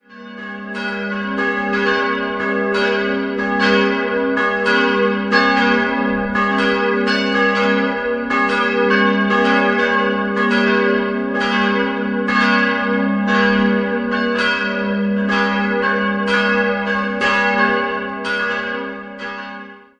3-stimmiges Geläut: g'-b'-c''